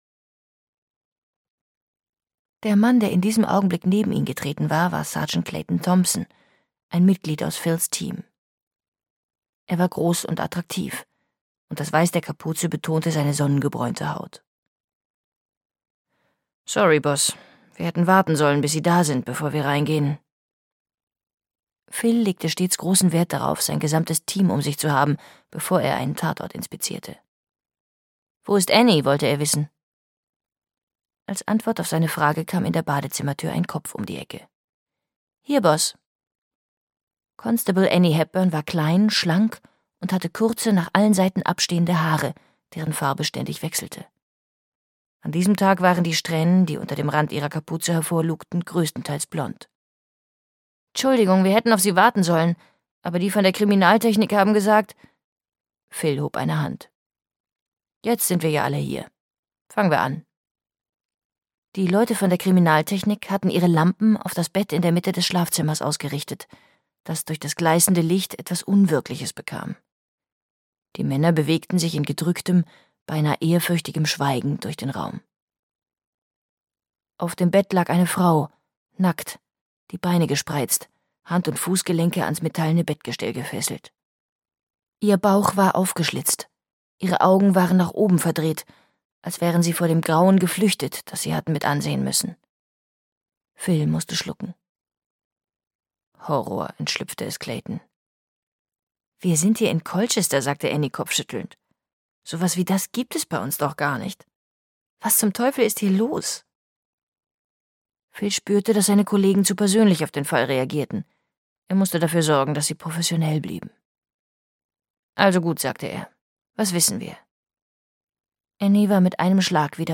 Entrissen (Ein Marina-Esposito-Thriller 1) - Tania Carver - Hörbuch